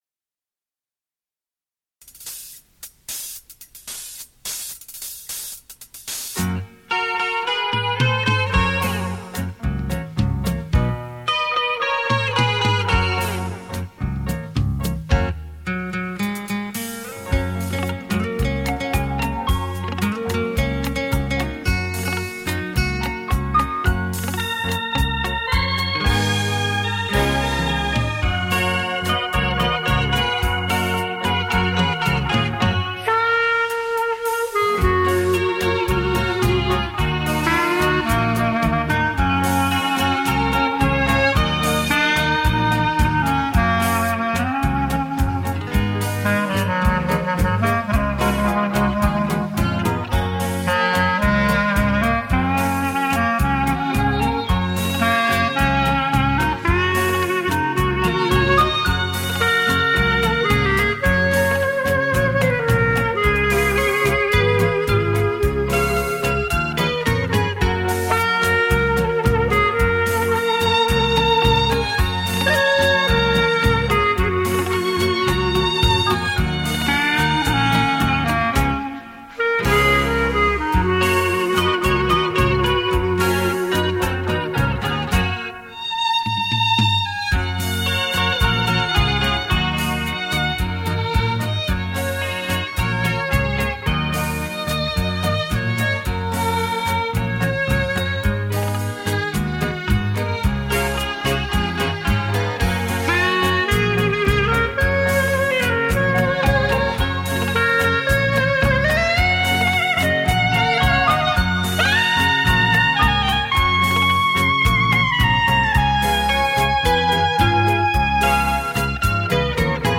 经典珍藏 黑胶CD黄金版